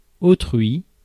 Ääntäminen
Synonyymit les autres Ääntäminen France: IPA: /o.tʁɥi/ Haettu sana löytyi näillä lähdekielillä: ranska Käännöksiä ei löytynyt valitulle kohdekielelle.